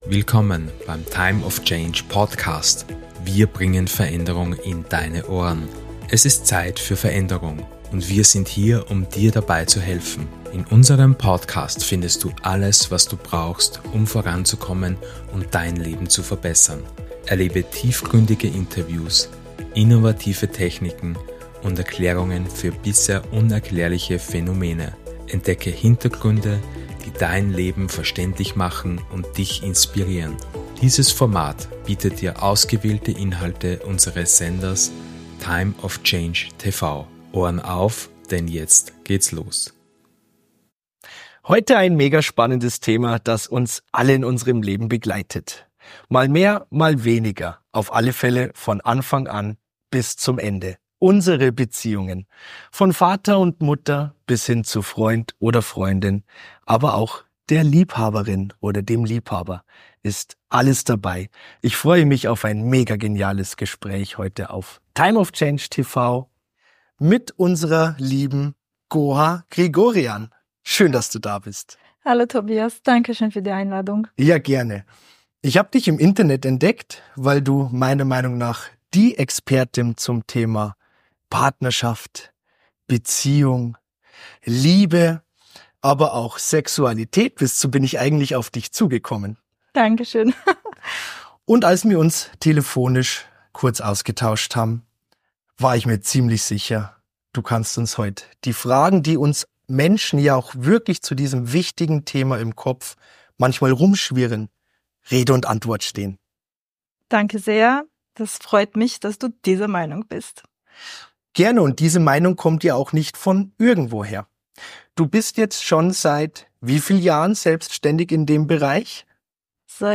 Dieses Interview wird Dir helfen, Deine Beziehungen zu verbessern und Dein volles Potenzial in der Liebe zu entfalten.